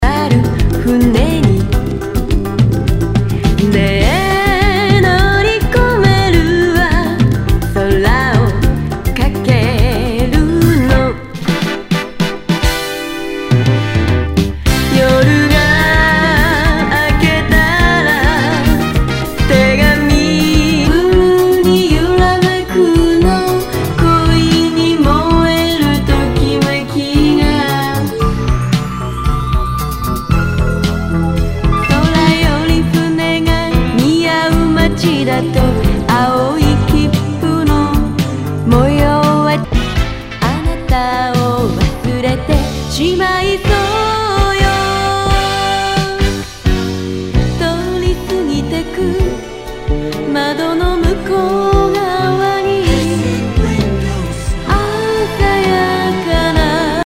和モノ/JAPANEASE GROOVE
ナイス！シティ・ポップ / Light Mellow 和モノ！！
全体にチリノイズが入ります